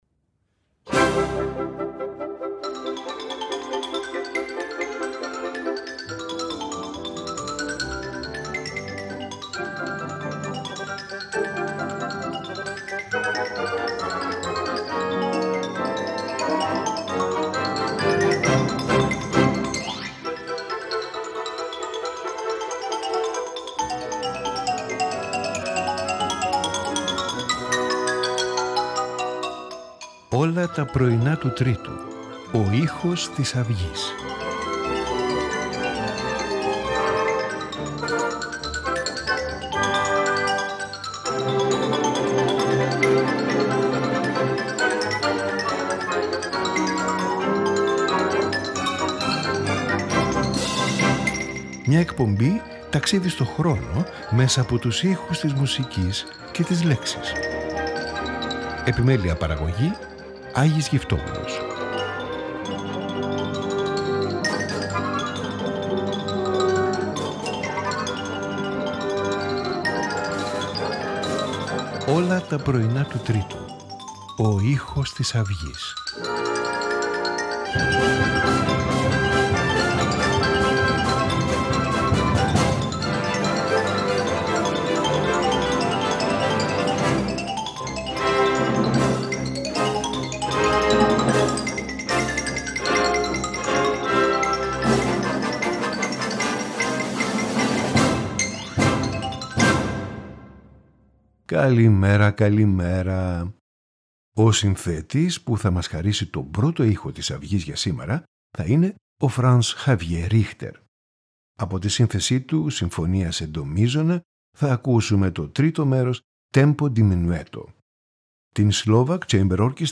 Franz Xaver Richter – Symphony in C, Part IIIFranz Ignaz von Beecke – String Quartet in C, Part IIIL. V. Beethoven – Piano Trio No 1, Op. 1, Part IJ.
Violin Concerto No 1
Piano Concerto No 4 in G minor
Sonata in E